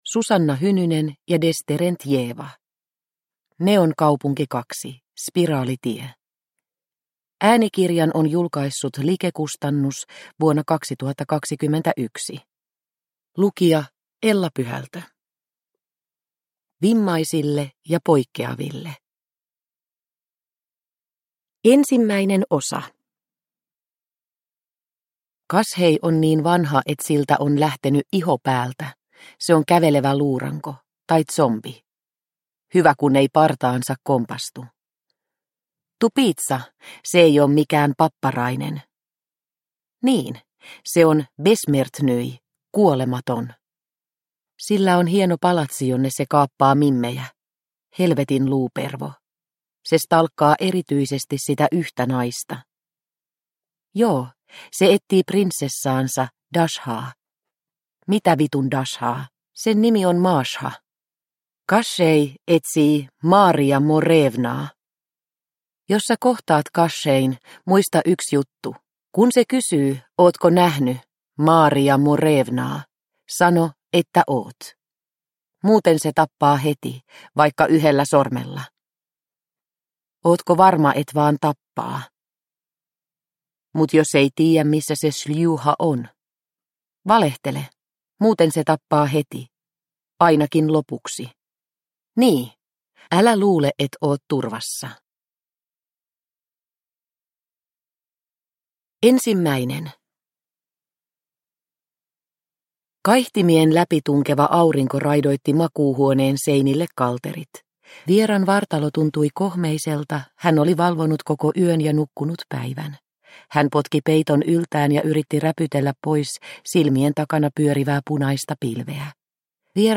Neonkaupunki 2 - Spiraalitie – Ljudbok – Laddas ner